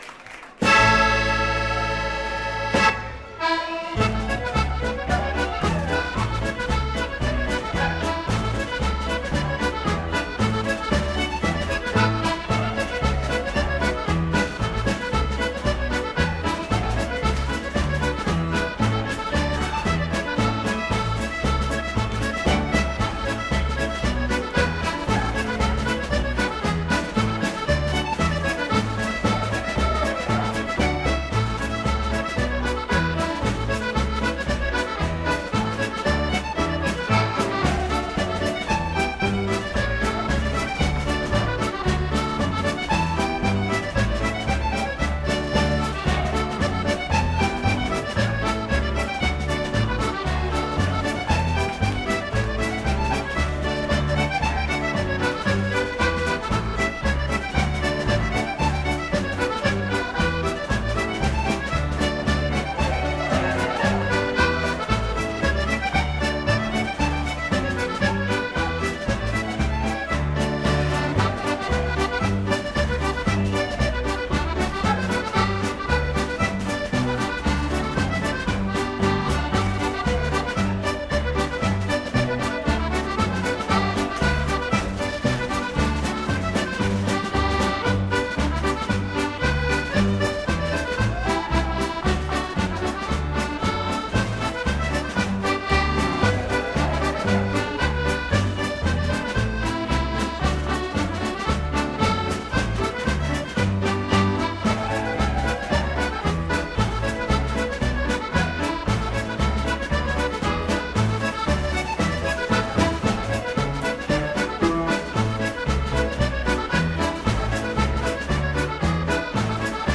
Genre: World.